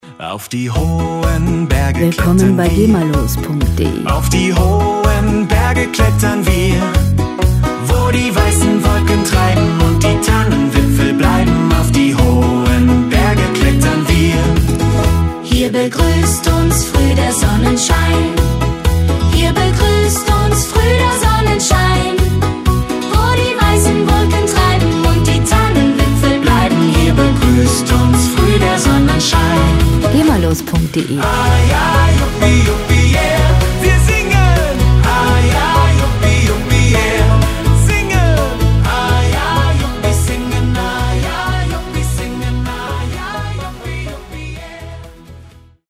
ist eine gemafreie Country-Pop-Neufassung mit eigenem Text
• Country-Pop